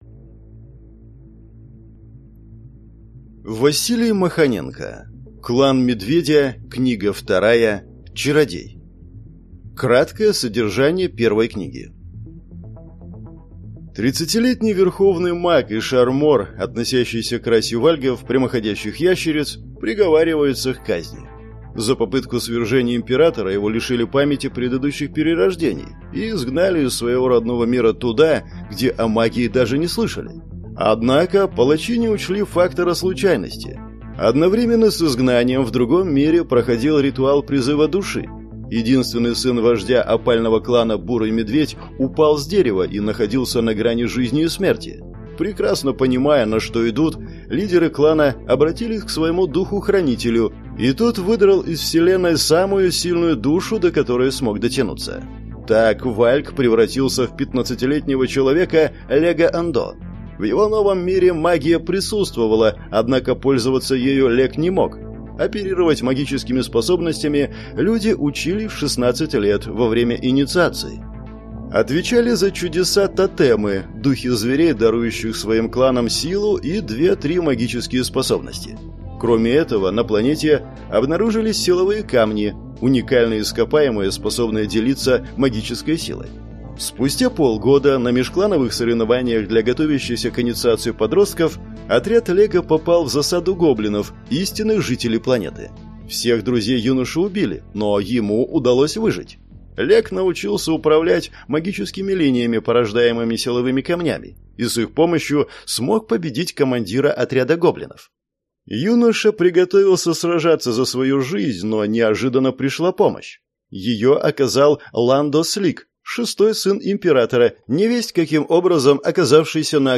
Аудиокнига Клан Медведя. Книга 2. Чародей | Библиотека аудиокниг